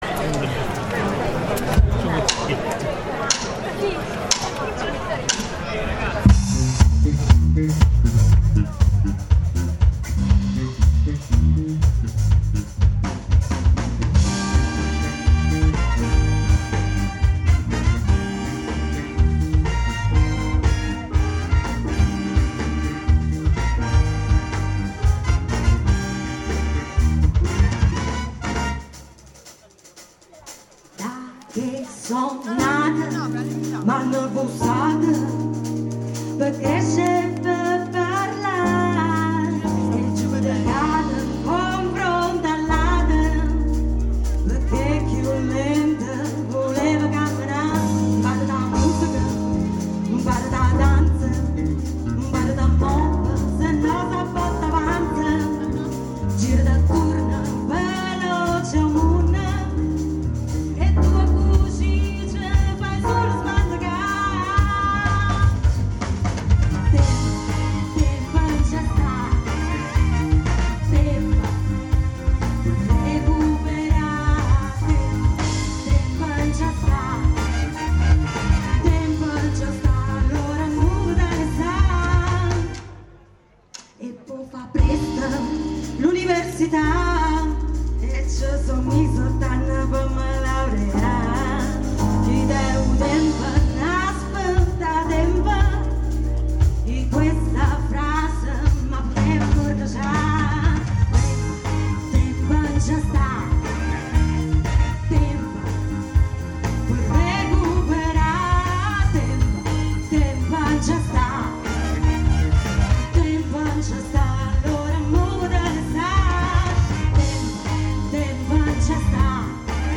Festival Della Canzone Dialettale Molisana 2018: Serate Premorienza
Le pochissime canzoni, da me registrate senza alcuna vera voglia di farlo, sono state posizionate alla rinfusa.
persona che ha una bella voce